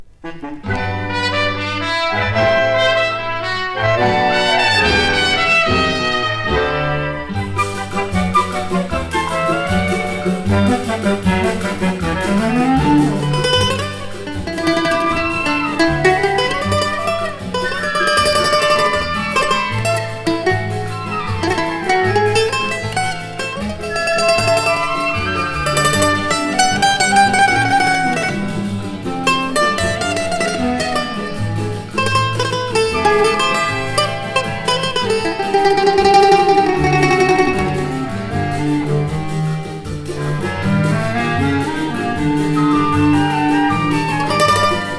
World, Latin, MPB, Samba, Choro　Brazil　12inchレコード　33rpm　Mono